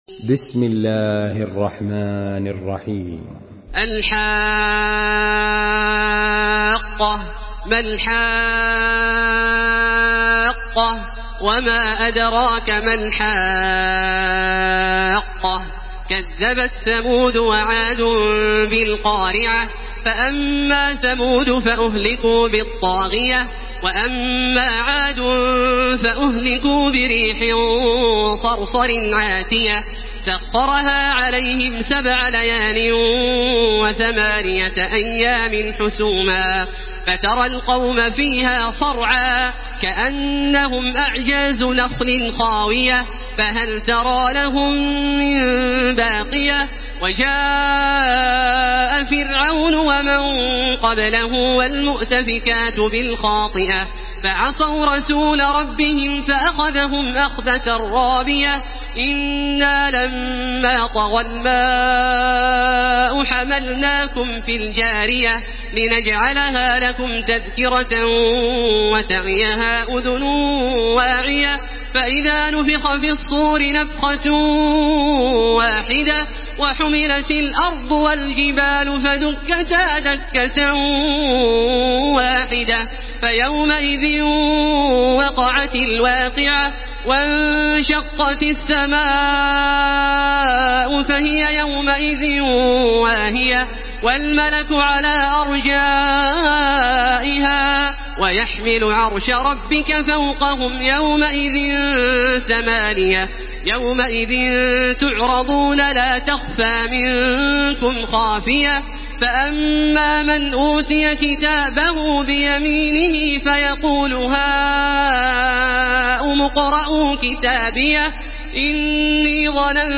Surah Al-Haqqah MP3 in the Voice of Makkah Taraweeh 1434 in Hafs Narration
Listen and download the full recitation in MP3 format via direct and fast links in multiple qualities to your mobile phone.
Murattal